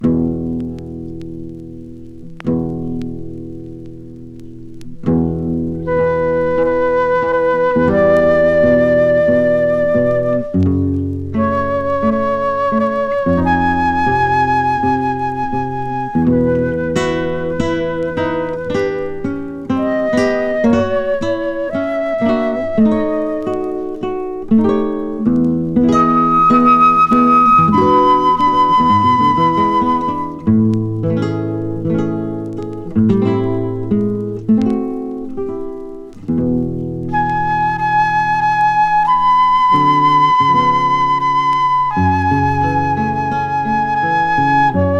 ギター、ベース、ドラム、フルートやテナーサックスによる演奏。
Jazz　USA　12inchレコード　33rpm　Mono